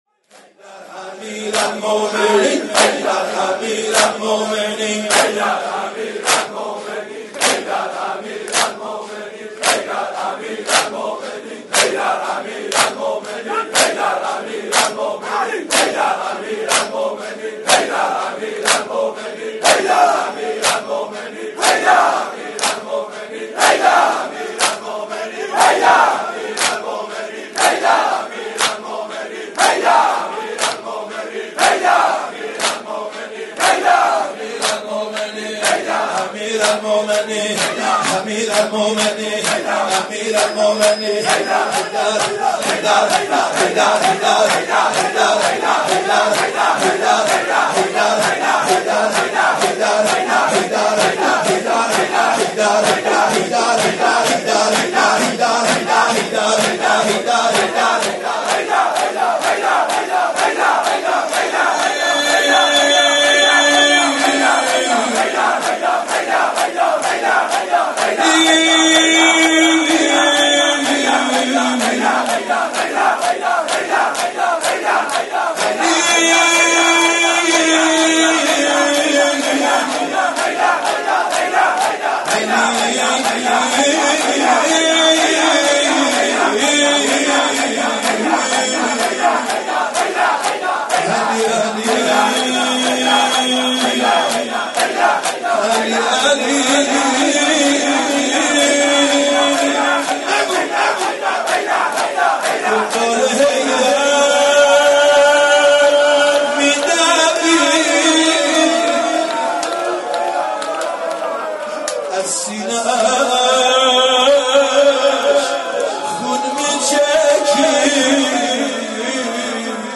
مداحی و نوحه
به مناسبت شهادت حضرت فاطمه زهرا(س)